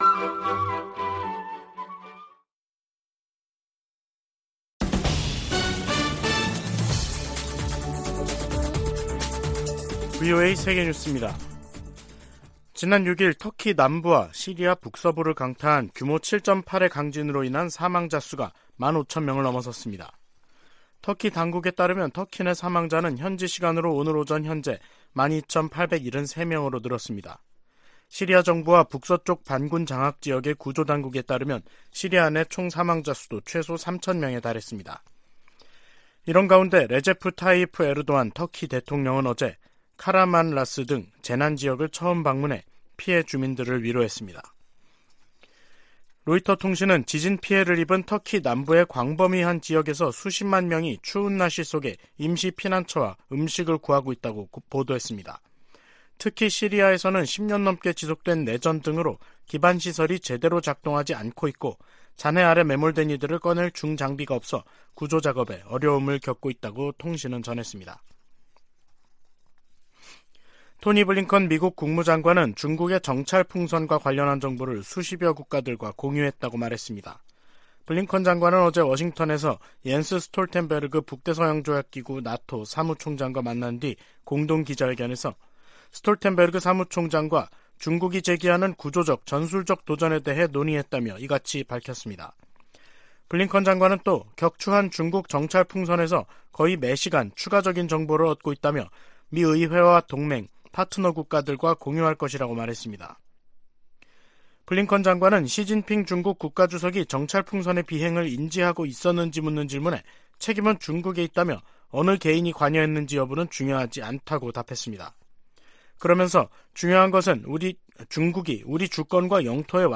VOA 한국어 간판 뉴스 프로그램 '뉴스 투데이', 2023년 2월 9일 2부 방송입니다. 북한 건군절 기념 열병식에서 고체연료 대륙간탄도 미사일, ICBM으로 추정되는 신형 무기가 등장했습니다. 북한의 핵・미사일 관련 조직으로 추정되는 미사일총국이 공개된 데 대해 미국 정부는 북한 미사일 개발을 억지하겠다는 의지를 확인했습니다.